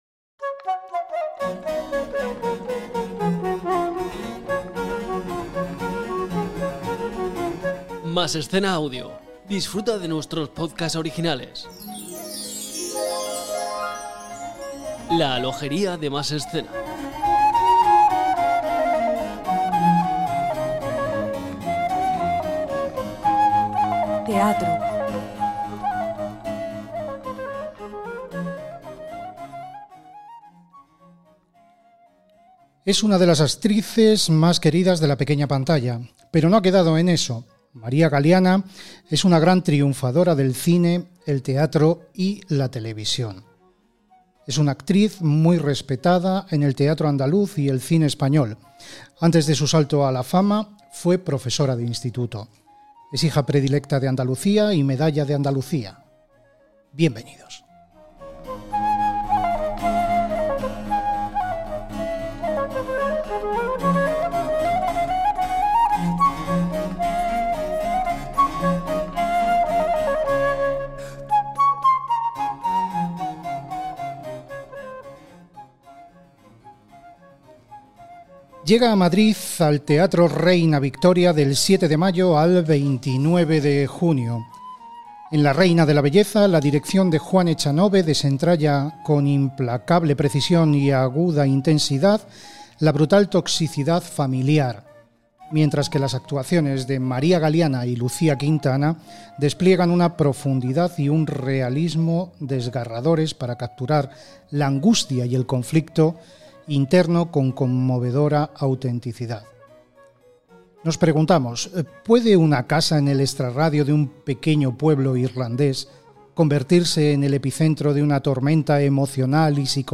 Entrevista a la actriz María Galiana - Masescena